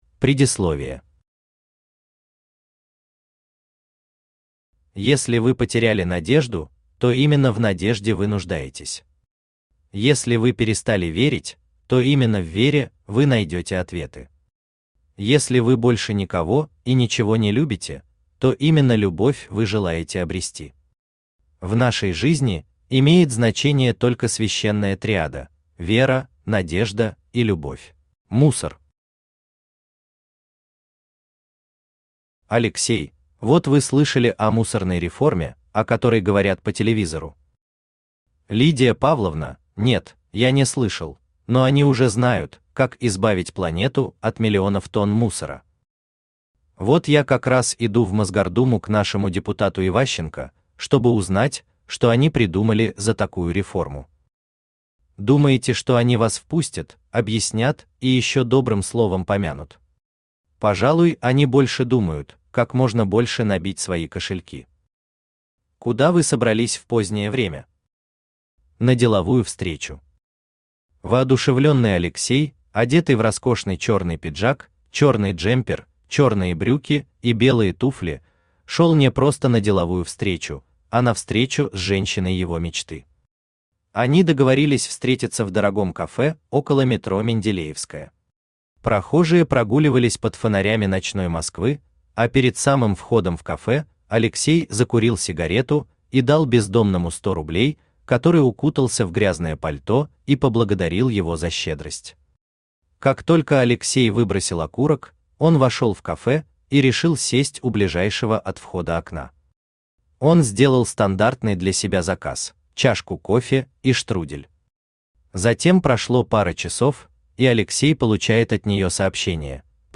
Аудиокнига Священная триада. Сборник | Библиотека аудиокниг
Aудиокнига Священная триада. Сборник Автор Виталий Александрович Кириллов Читает аудиокнигу Авточтец ЛитРес.